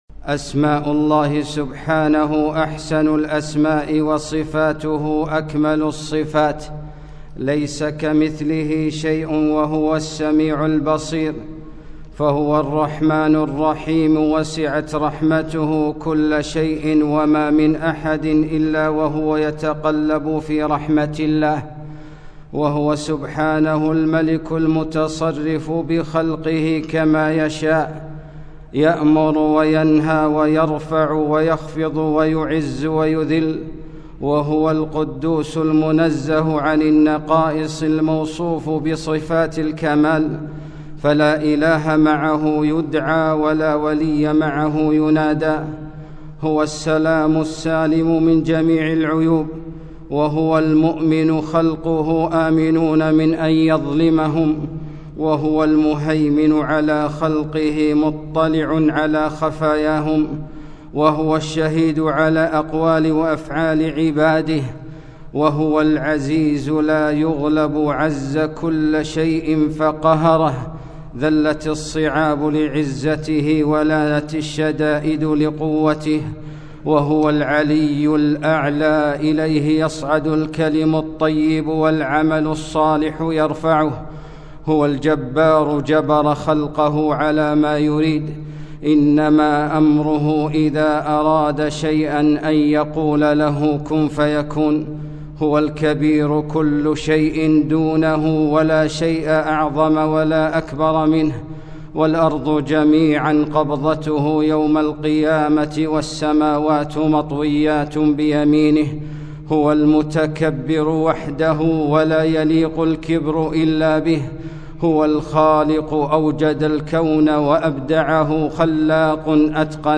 خطبة - ليس كمثله شيئ